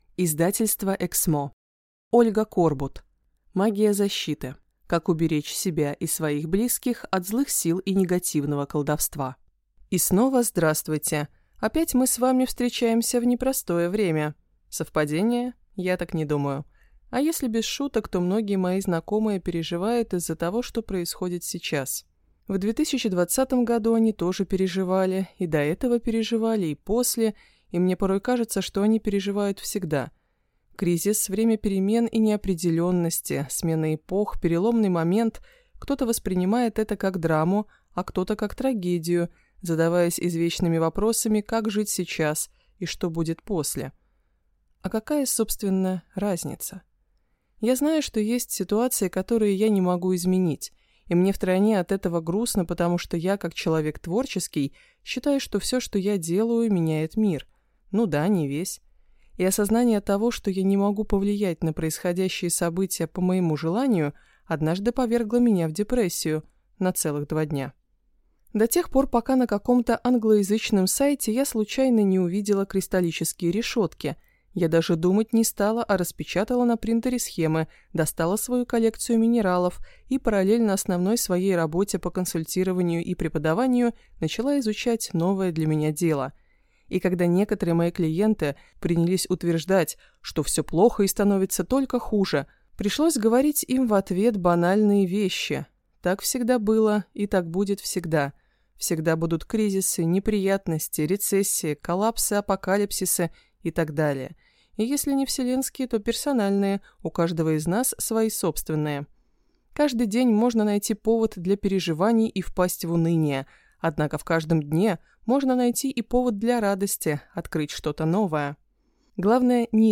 Аудиокнига Магия защиты. Как уберечь себя и своих близких от злых сил и негативного колдовства | Библиотека аудиокниг